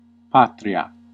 Ääntäminen
UK : IPA : [ˈkʌntri] US : IPA : [ˈkʌntri]